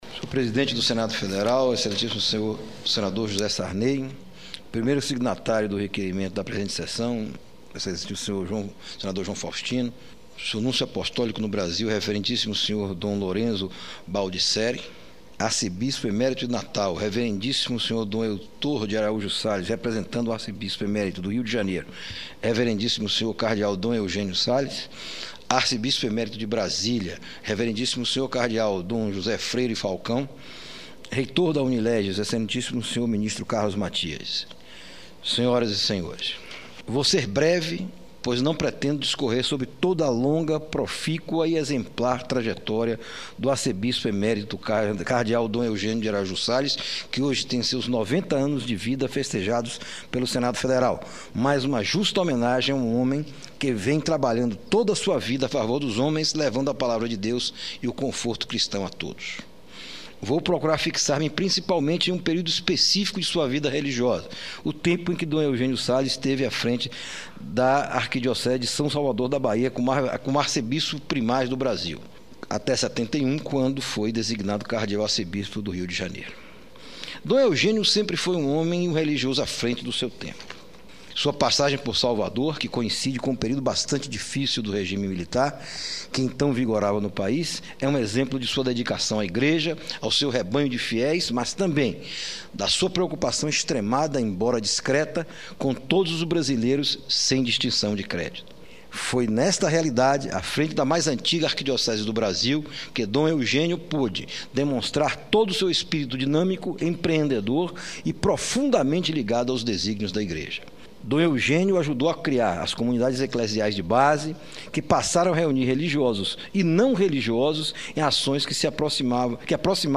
Pronunciamento de homenagem do senador Antonio Carlos Júnior